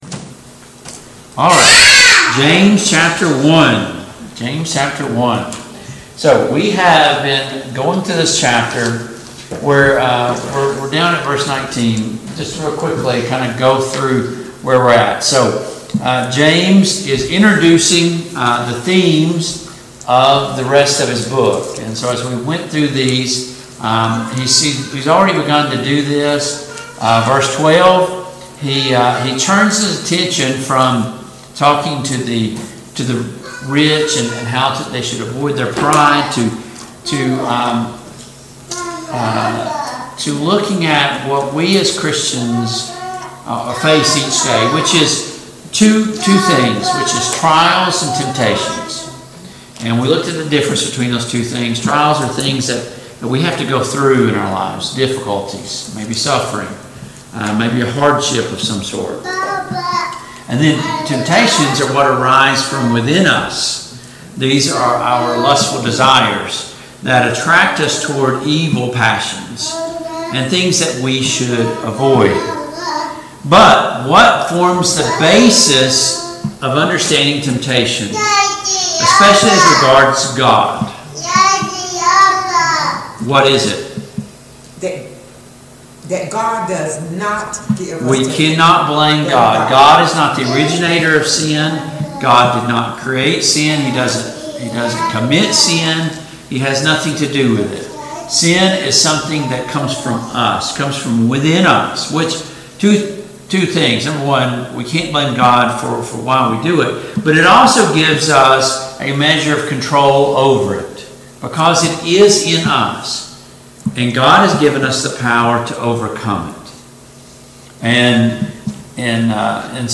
Study of James and 1 Peter and 2 Peter Passage: James 1:12-21 Service Type: Family Bible Hour « Does God expect us to have a “blind” faith?